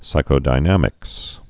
(sīkō-dī-nămĭks, -dĭ-)